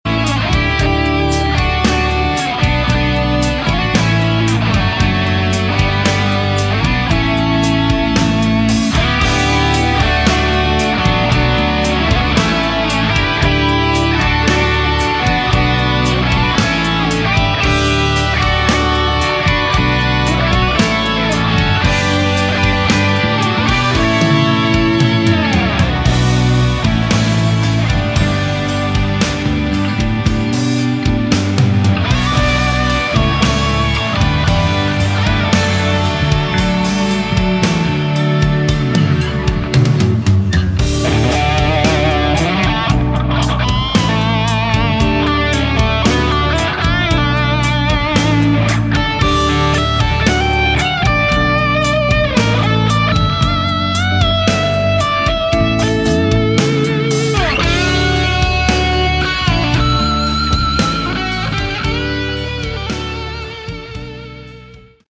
Category: Hard Rock
bass, vocals
guitar, vocals
lead vocals
drums
guitars, vocals